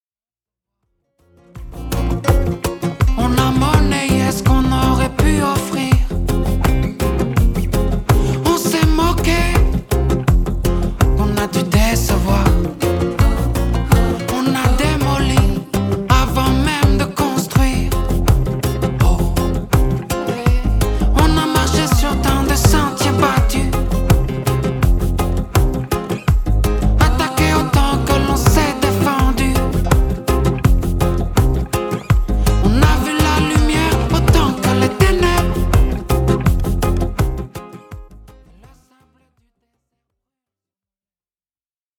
reggae roots